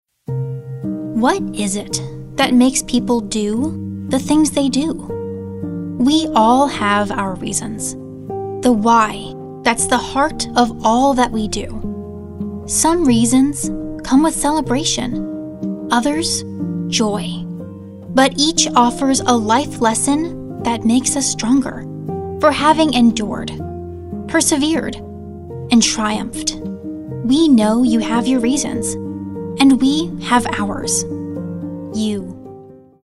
anti-announcer, caring, compelling, confessional, inspirational, motivational, sincere, soft-spoken, thoughtful, warm, young, young adult